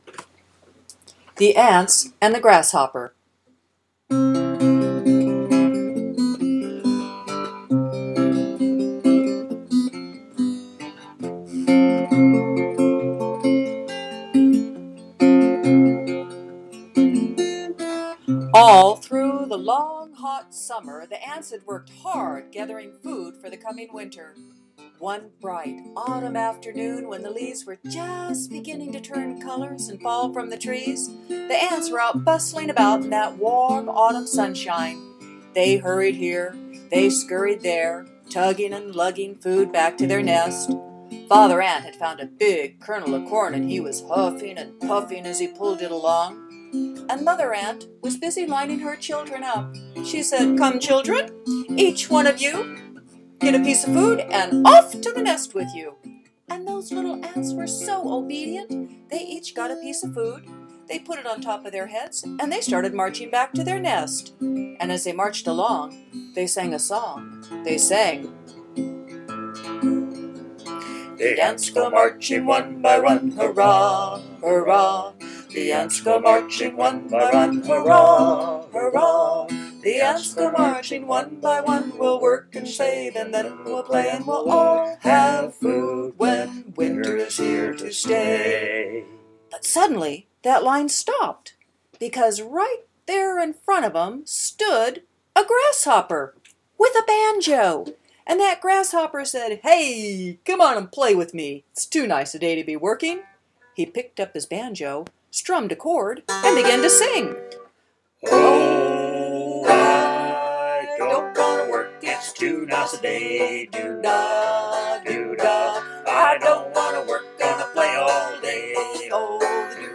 Story Summary: (Fable: 4 minutes) This fun fable provides a famous moral lesson about hard work and preparation.
Grasshopper.mp3